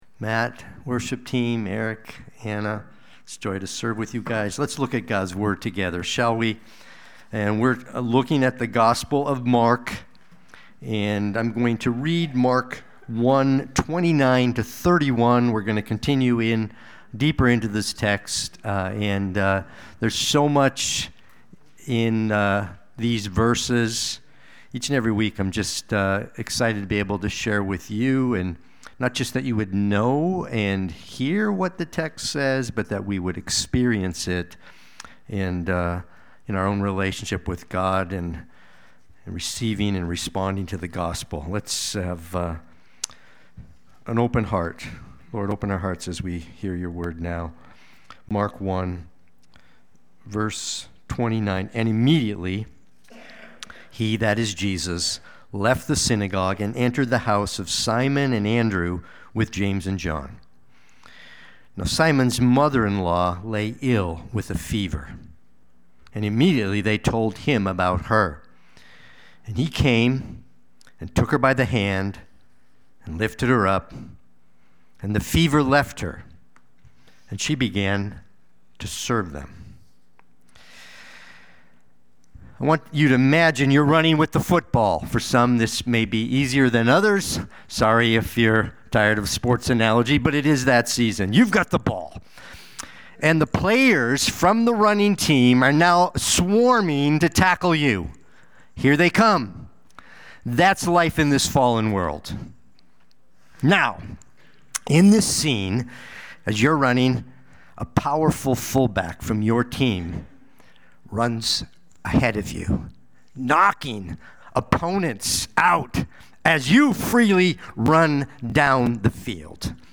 Watch the replay or listen to the sermon.